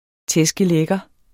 Udtale [ ˈtεsgəˈ- ]